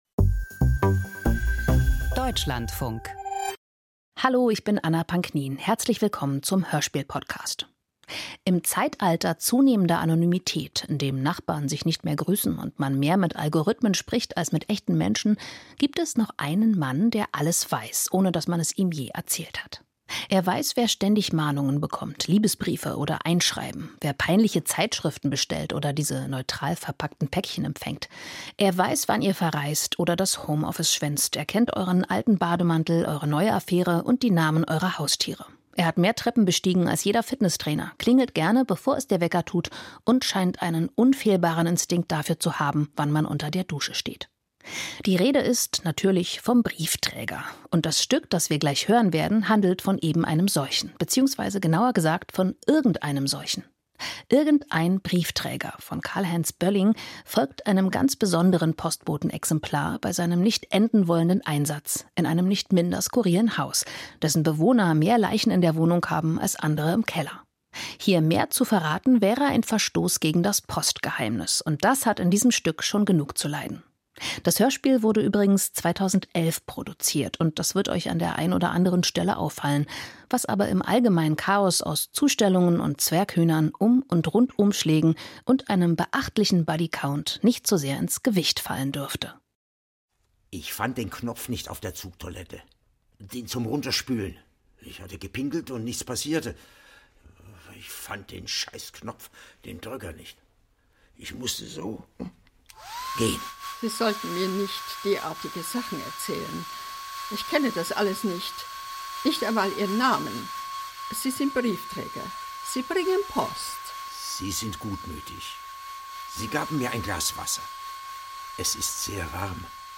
Hörspiel: Ein Mietshaus voller Geschichten - Irgendein Briefträger